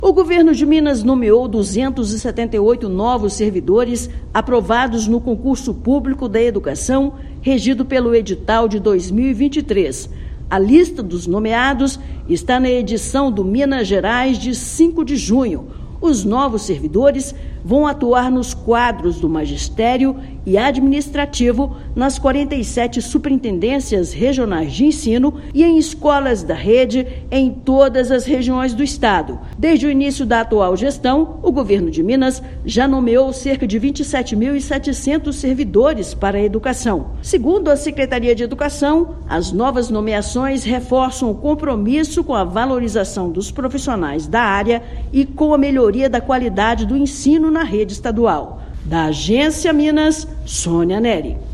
Com mais de 6,7 mil nomeações no concurso vigente, Estado amplia quadro de profissionais e reafirma compromisso com a educação pública. Ouça matéria de rádio.